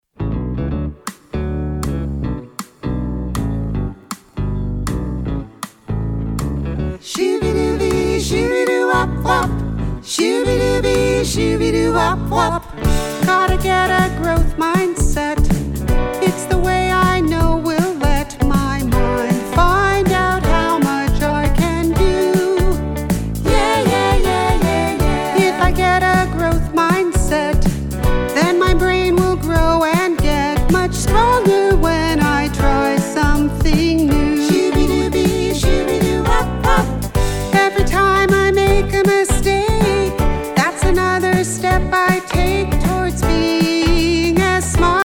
Listen to a sample of this song.